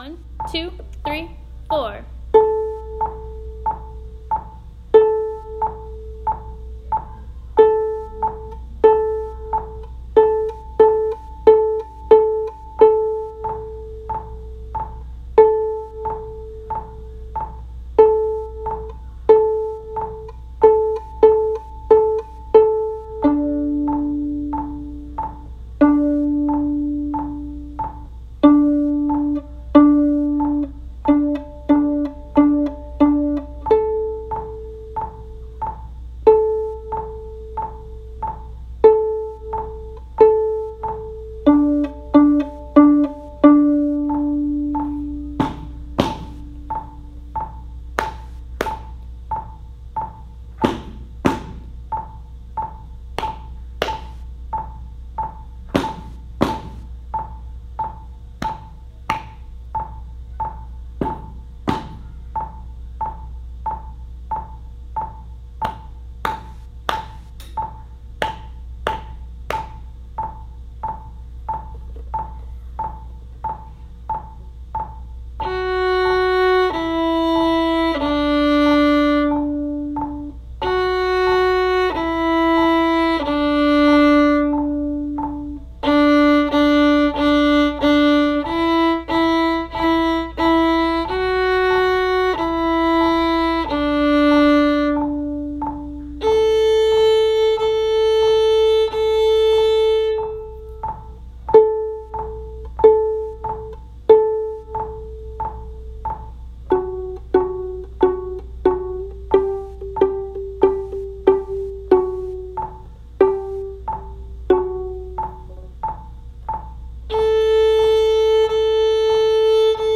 “Hot When Done” – Play Along Tracks
Here are play along tracks for “Hot When Done” complete with your part and a metronome.
Hot-When-Done-Viola.m4a